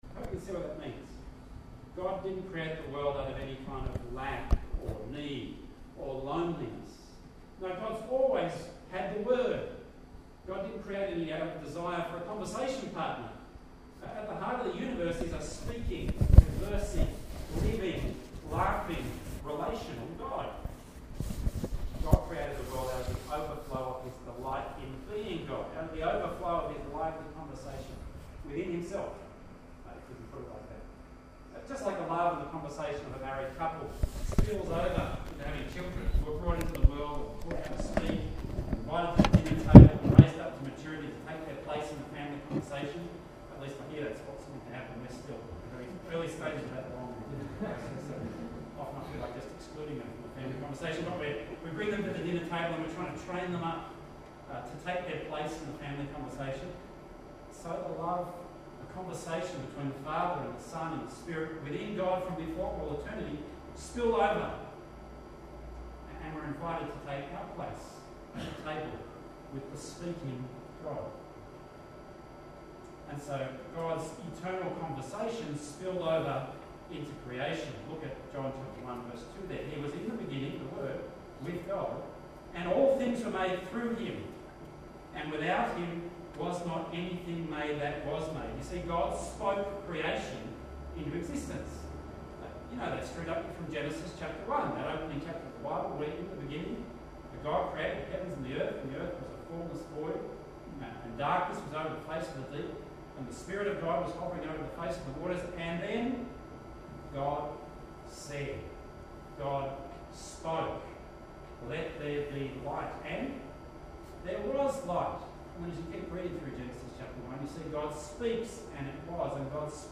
Sorry about the audio quality, the headset mic was not working.
Doctrine of Revelation Passage: Romans 1:18-32, Psalm 19:1-14 Service Type: Sunday Morning Sorry about the audio quality